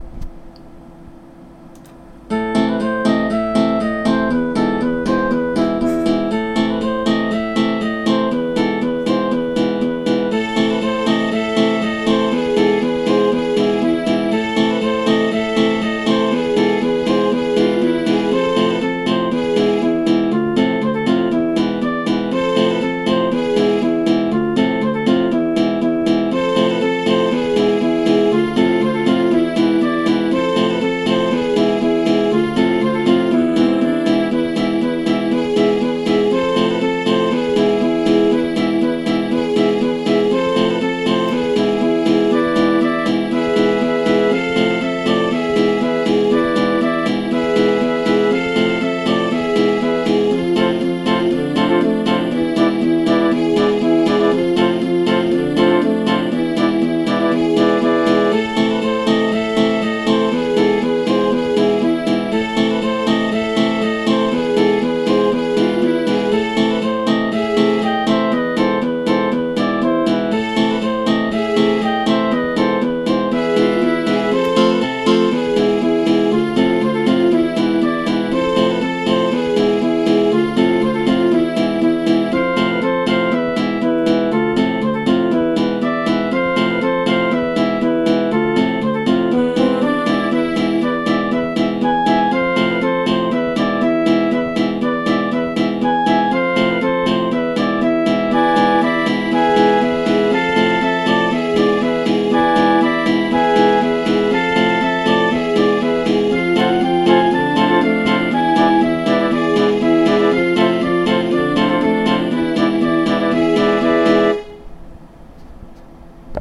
Balakhan (L. Jannkin) (Bourrée orientale) - Compositions
Cette bourrée 2 temps est une fantaisie, mais qui a toujours beaucoup de succès en bal folk.